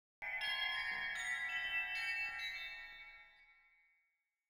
AV_Bell_FX_01
AV_Bell_FX_01.wav